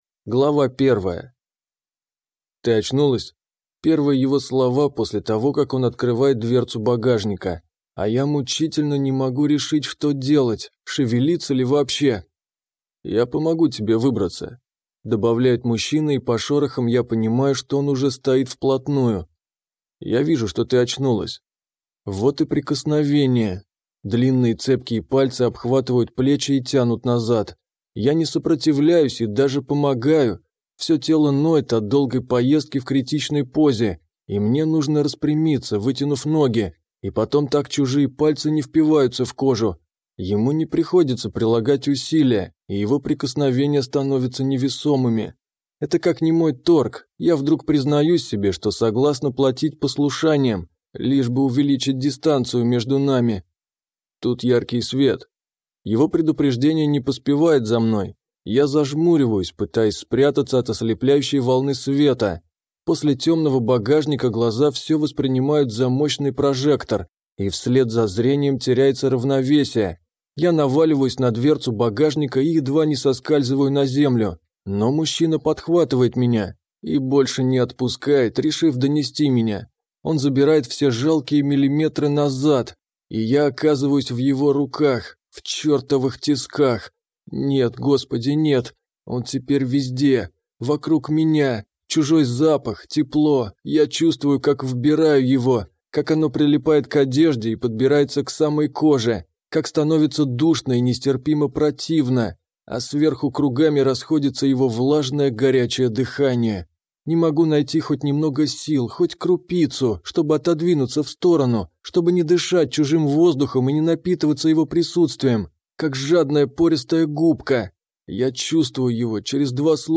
Аудиокнига Вдовец | Библиотека аудиокниг